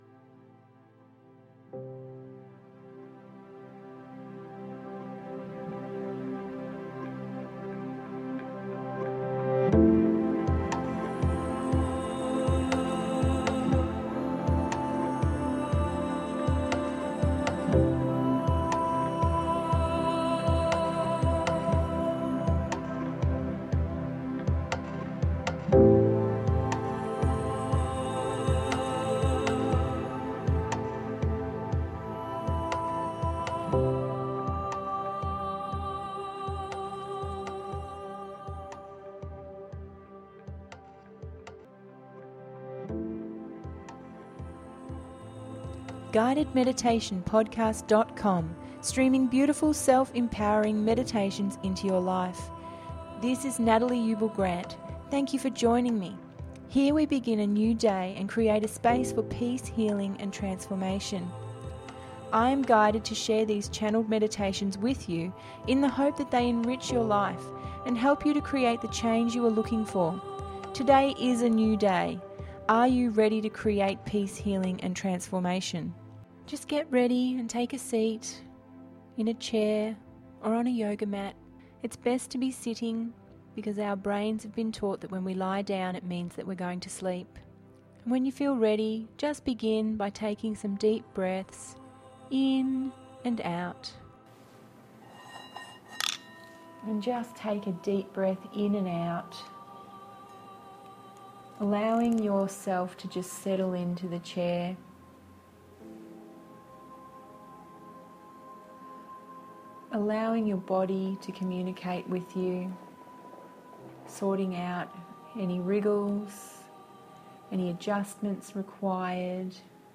Waves crashing against the cliff and the sound of the ocean in the background.